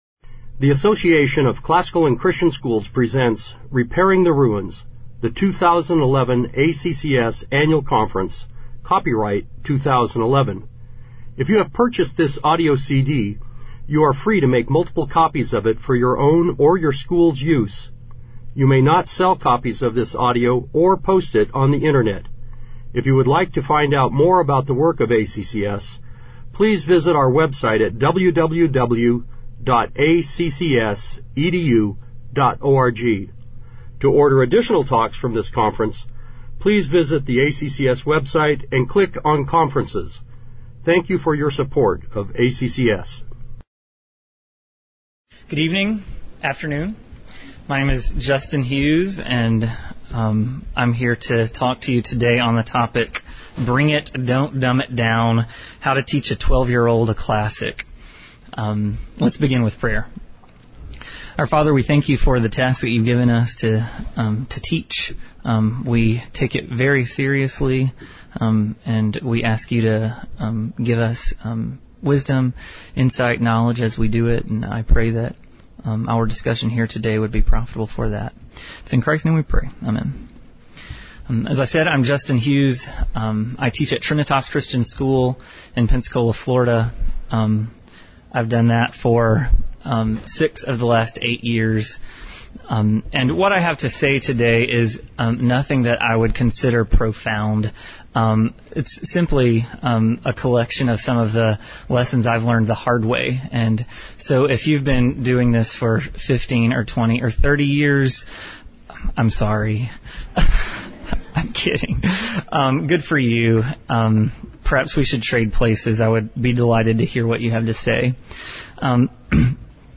2011 Workshop Talk | 1:06:17 | K-6, Literature
This workshop will offer some suggestions for teaching classical texts to young students while maintaining the integrity of the works and considering the frame of the students. Speaker Additional Materials The Association of Classical & Christian Schools presents Repairing the Ruins, the ACCS annual conference, copyright ACCS.